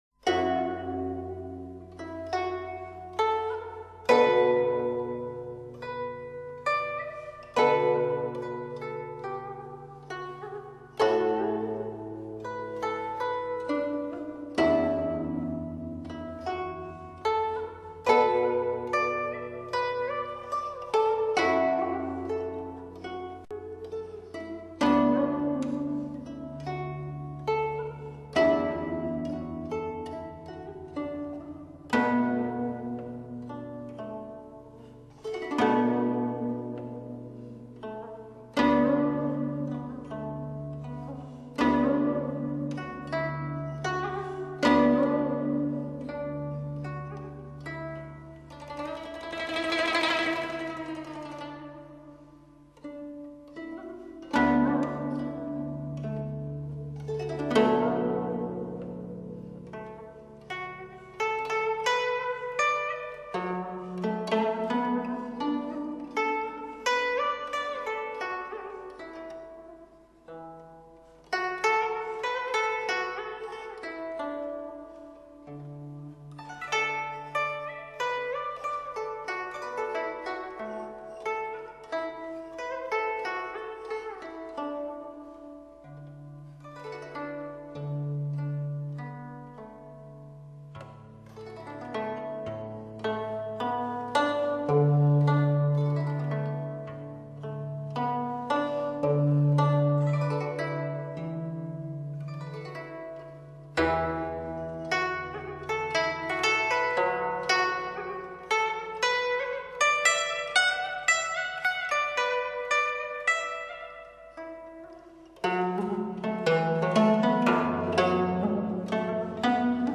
演奏水准超一流
古筝